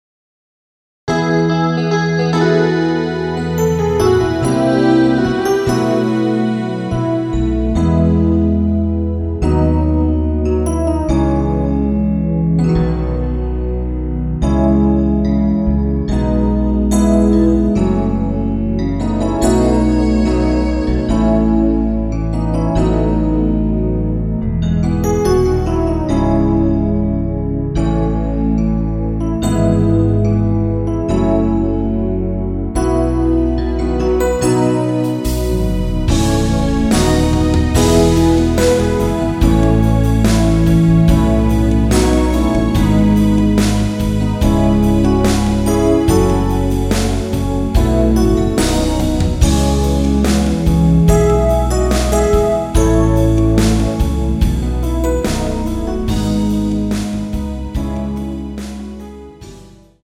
페이드 아웃곡이라 라이브하기좋게 엔딩을 만들어 놓았습니다.
원키에서(-2)내린 MR입니다.
앞부분30초, 뒷부분30초씩 편집해서 올려 드리고 있습니다.